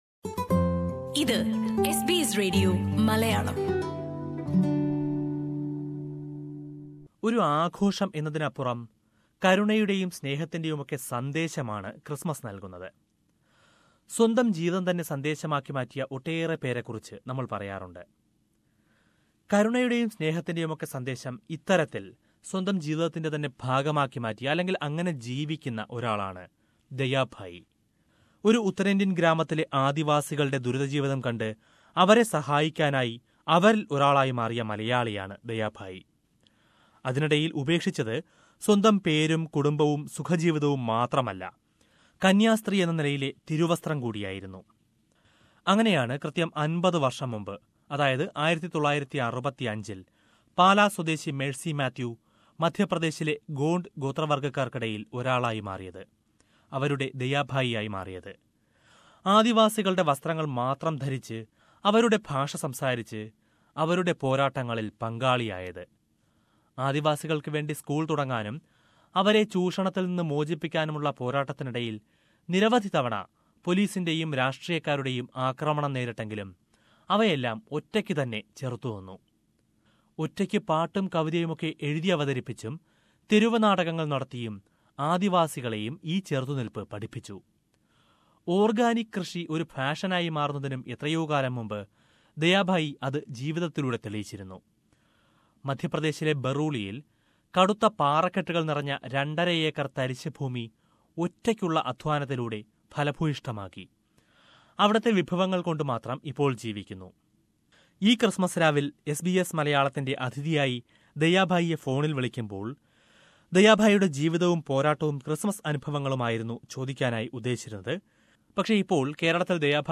A person who has left all her comfort to live with tribals in Madhyapradesh, and to teach them. SBS Malayalam Radio talks to Dayabai.